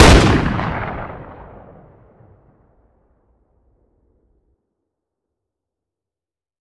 weap_western_fire_plr_atmo_ext1_01.ogg